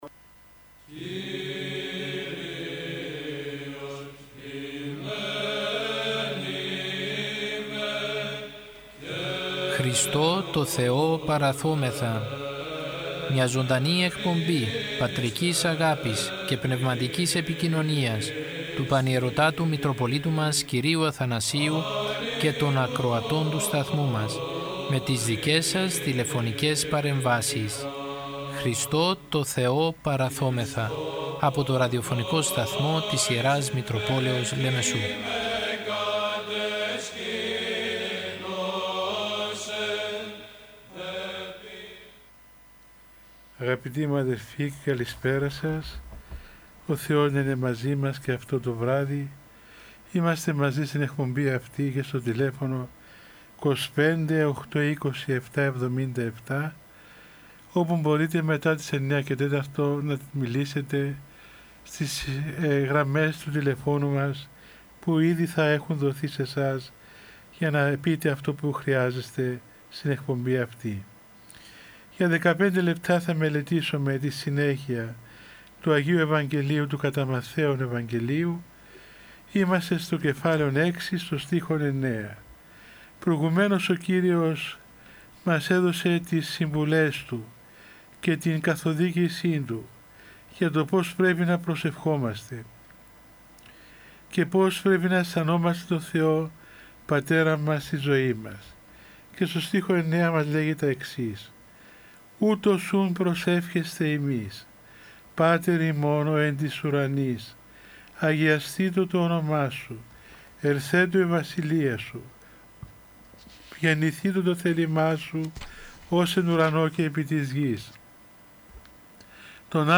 Ο Πανιερώτατος Μητροπολίτης Λεμεσού κ. Αθανάσιος μέσω του ραδιοφωνικού σταθμού της Μητροπόλεως του καθημερινά επικοινωνεί με το ποίμνιο με τηλεφωνικές συνδέσεις και απευθύνει παρηγορητικό λόγο για την δοκιμασία που περνάει ο Ορθόδοξος λαός για την πανδημία με κλειστές εκκλησίες και απαγορεύσεις.